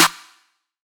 SOUTHSIDE_snare_in_da_room.wav